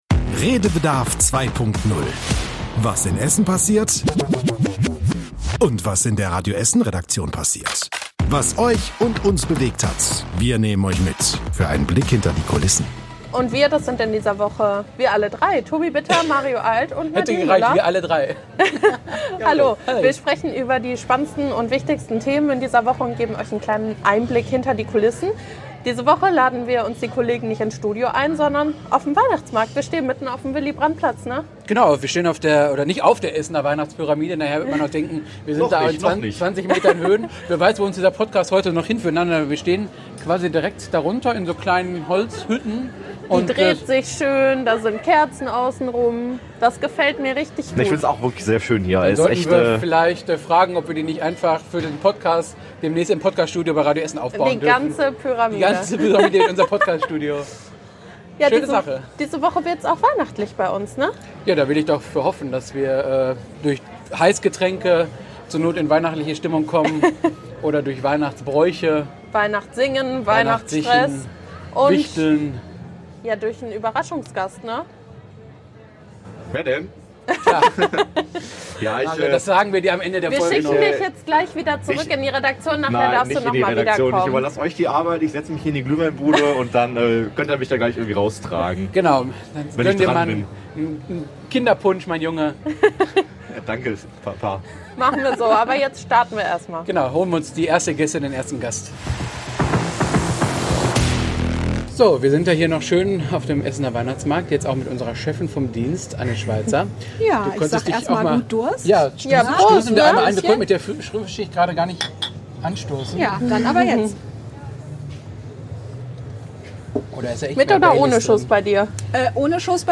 #59 Radio Essen von der Weihnachtspyramide ~ Redebedarf 2.0 - Die spannendsten Geschichten der Woche mit Blick hinter die Kulissen Podcast
Beschreibung vor 1 Jahr In der letzten Folge des Jahres hat es das Redebedarf 2.0-Team auf den Weihnachtsmarkt verschlagen. Bei Heißgetränken haben wir mit den Kollegen über Weihnachtsstress, Lieder und Tannenbäume gesprochen.